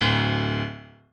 piano9_21.ogg